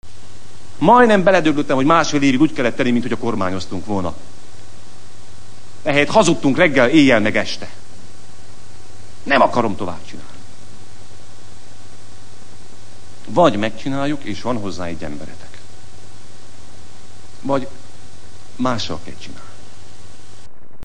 A miniszterelnök őszödi beszédének szöveghű részletei: